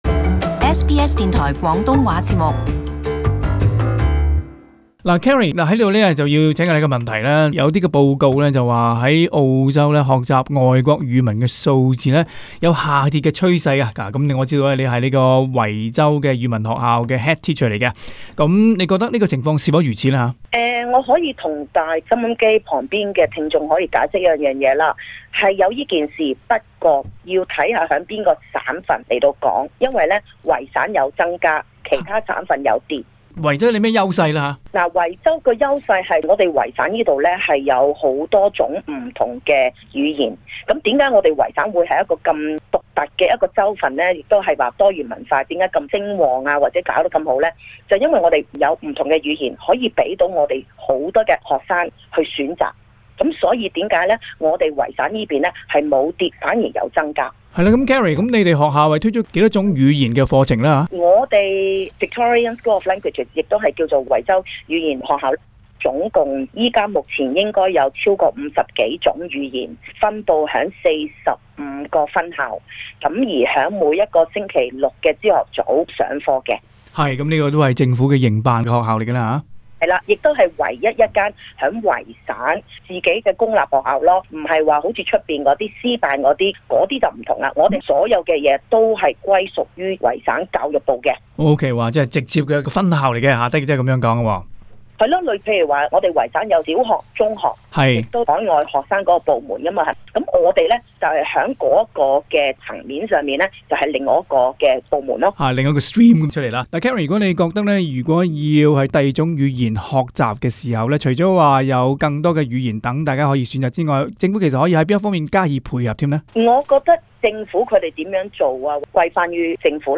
【社區專訪】維州學習第二種語言的數字勝過其他州份